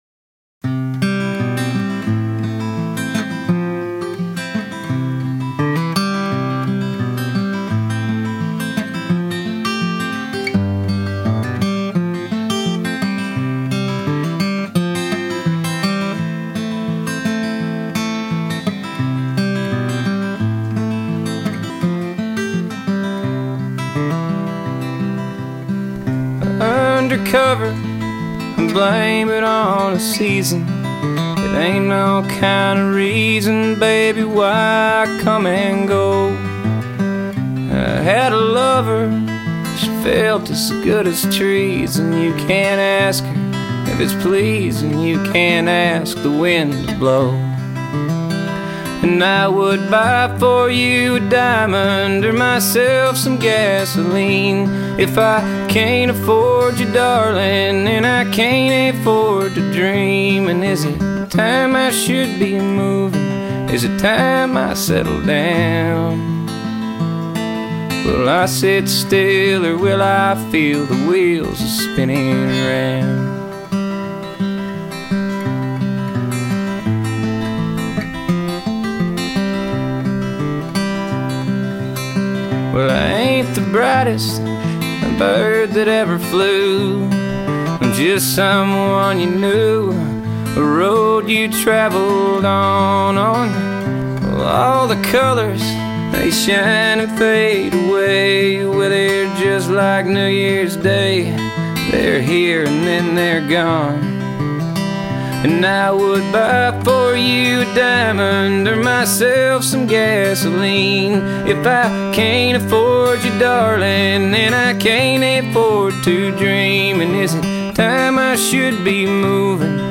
lead guitar
fiddle
bass
drums